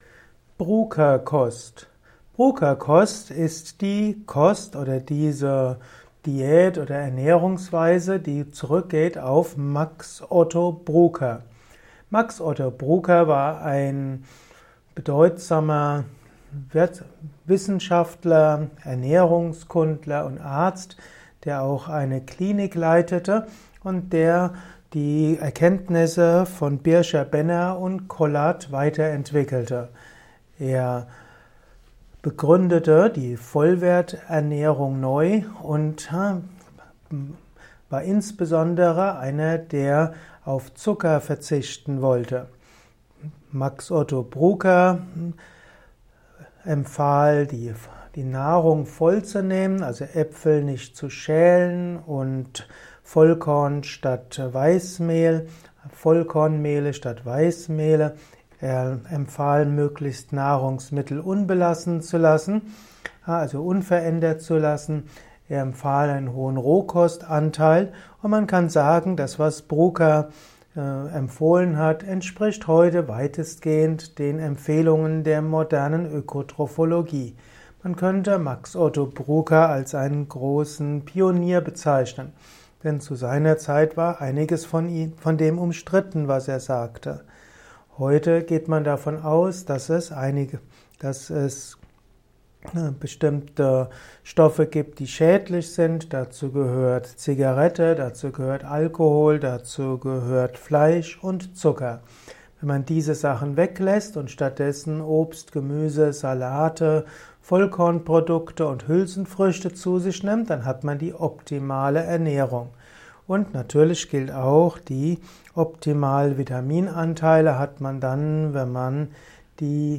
Erfahre mehr über die Bruker-Kost in diesem Kurzvortrag